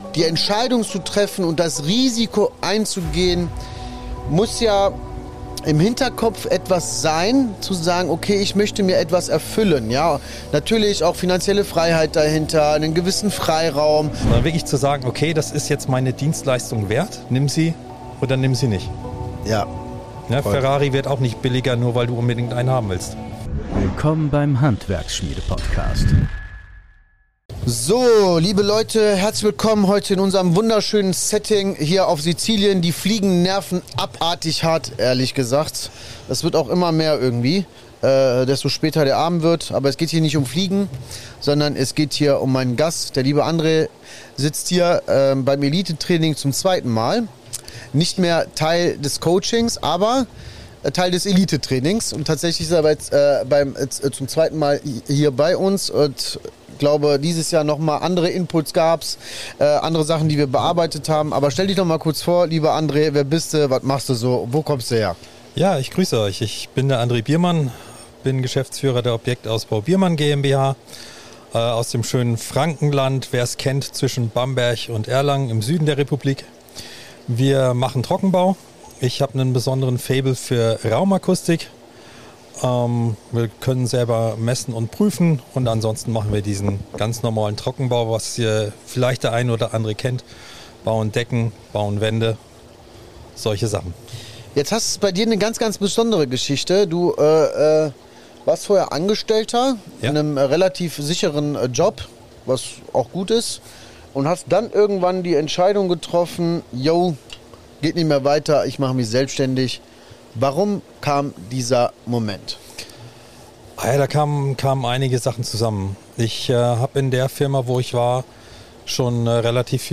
Ein Gespräch über Verantwortung, Freiheit und echte Entscheidungen im Handwerk.